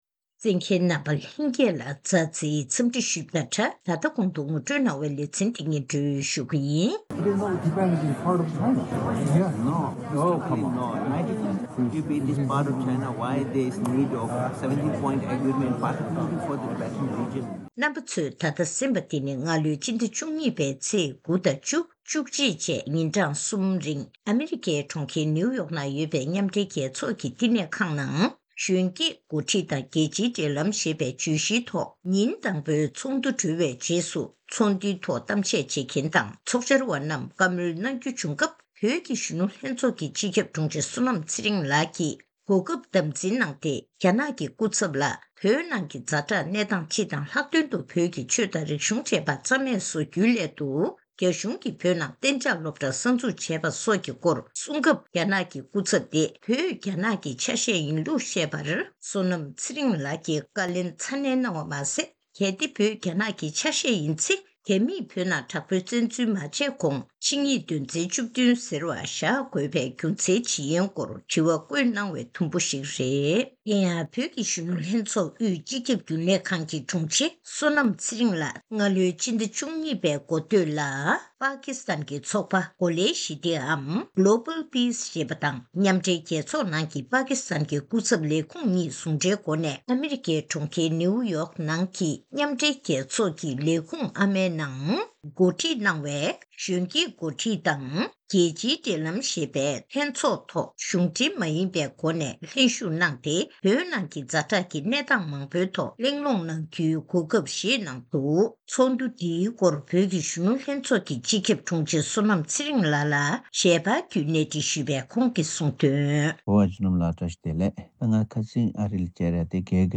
གནས་འདྲི་ཞུས་ནས་གནས་ཚུལ་ཕྱོགས་བསྒྲིགས་ཞུས་པ་ཞིག་གསན་རོགས་གནང་།